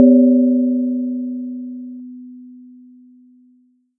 传统的亚洲打击乐
描述：听起来像传统的亚洲打击乐器！